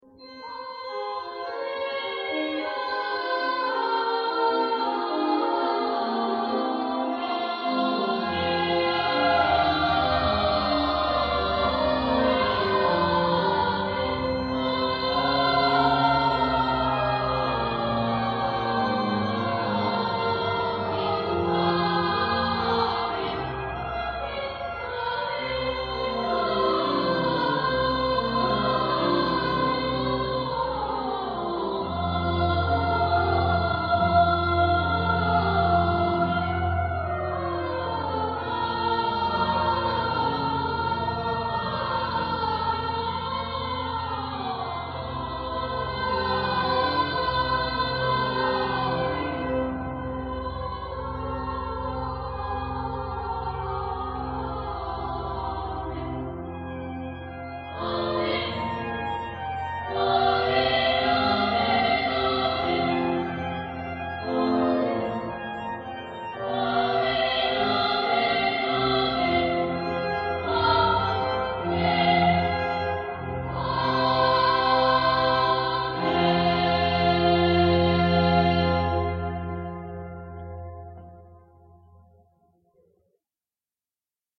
Grabaciones históricas realizadas
de varios cantos de la escolanía